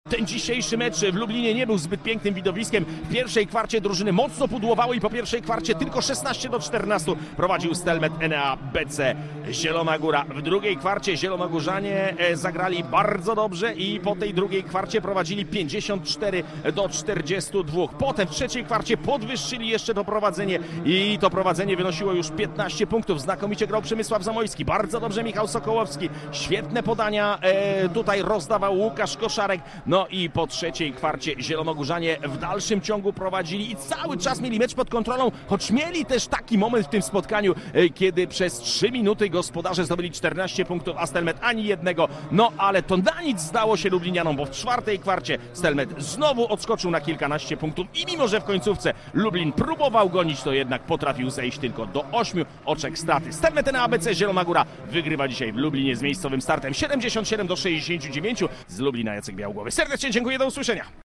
Pomeczowa relacja